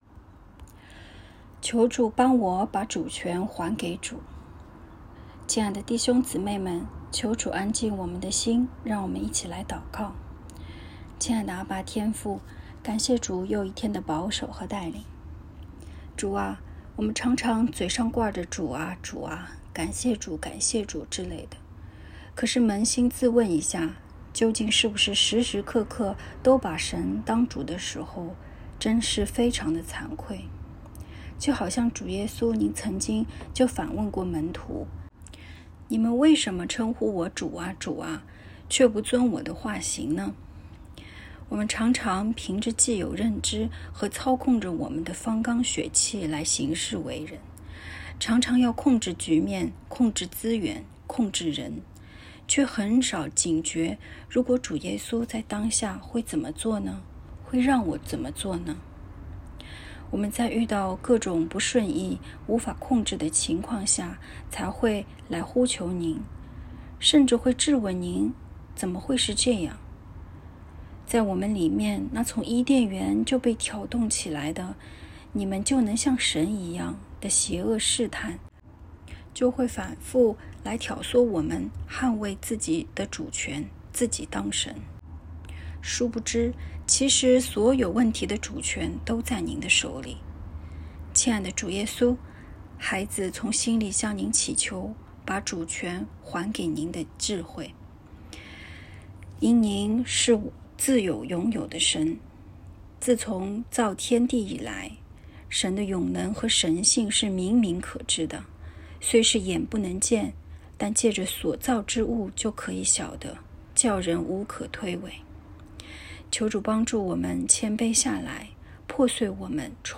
✨晚祷时间✨4月5日（周二）